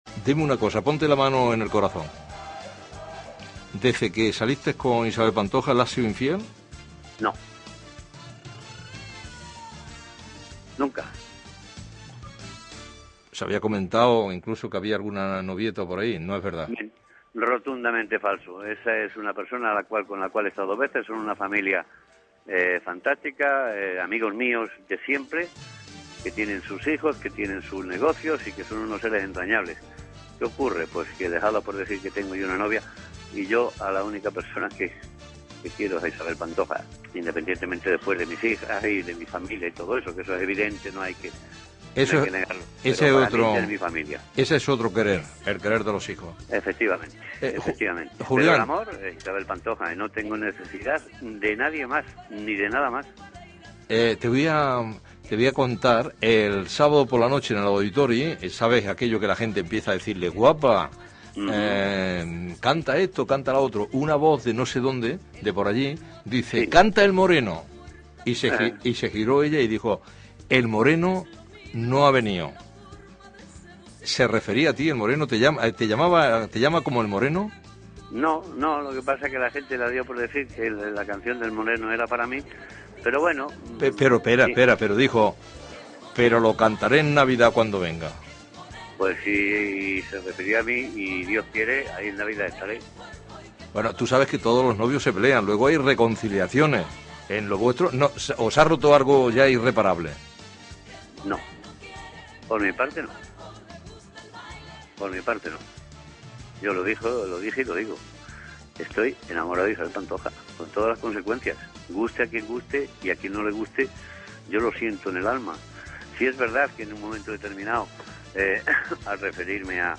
Entrevista a Julián Muñoz, exparella de la cantant Isabel Pantoja sobre la seva relació sentimental, l'estada a la presó i l'anunci dels concerts "Passió per Catalunya" d'Isabel Pantoja